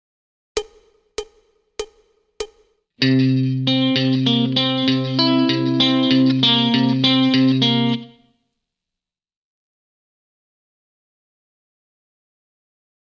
Guitar Styles and Techniques